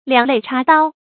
兩肋插刀 注音： ㄌㄧㄤˇ ㄌㄟˋ ㄔㄚ ㄉㄠ 讀音讀法： 意思解釋： 兩邊肋骨插上刀。表示不怕死；敢于赴湯蹈火。